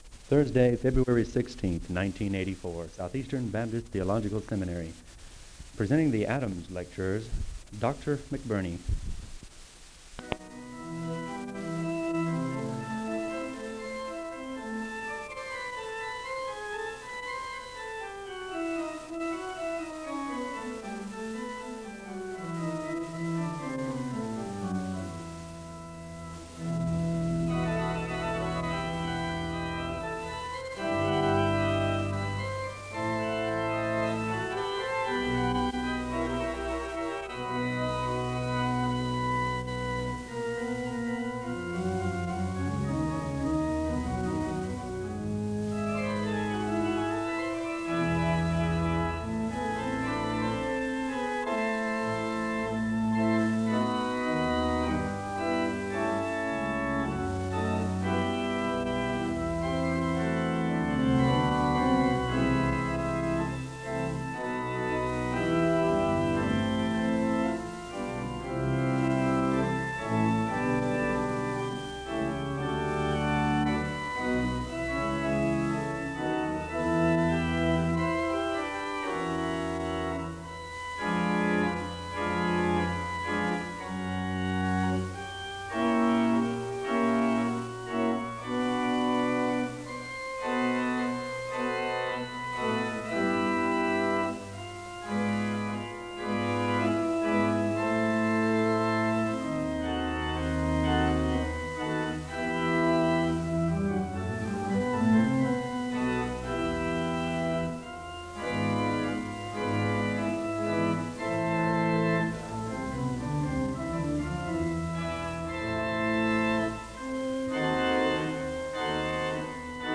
The service begins with organ music (00:00-05:06). The speaker gives a word of prayer (05:07-07:32).
He covers the many aspects of a person’s identity, and examines the tensions in ministry, particularly the minister’s role and relationship to his congregants (11:13-41:43). The service ends with a word of prayer (41:44-43:26).